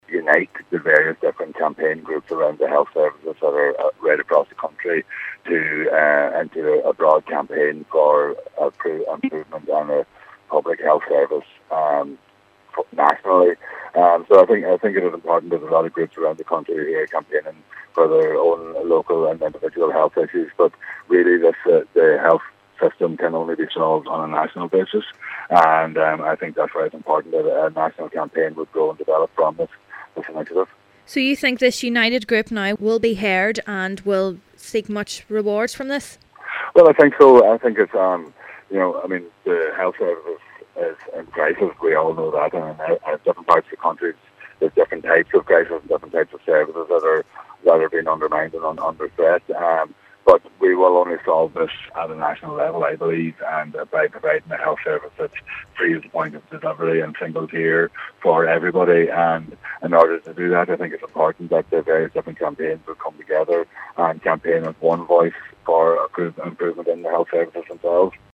Donegal Deputy Thomas Pringle is among a number of elected representatives speaking at the meeting, he believes the health crisis can only be solved on a national level: